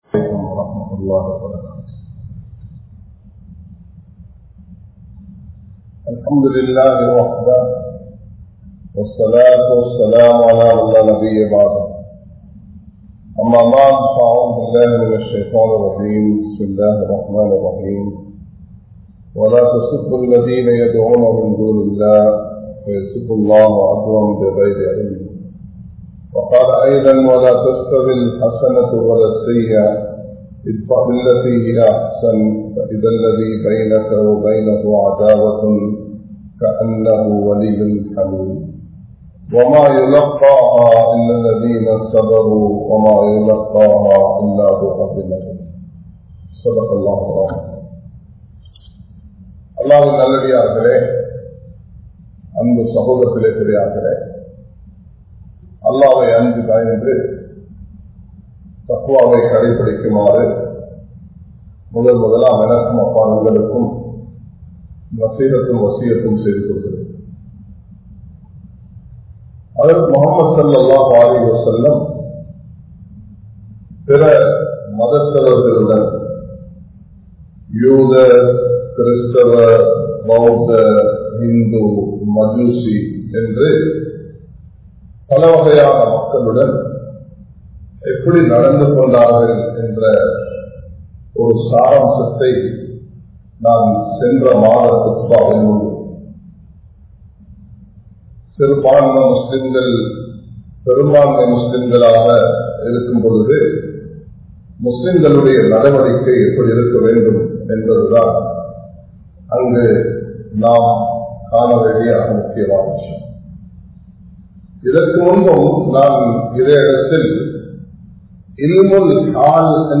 Naattin Thatpoathaya Nilamai | Audio Bayans | All Ceylon Muslim Youth Community | Addalaichenai
Samman Kottu Jumua Masjith (Red Masjith)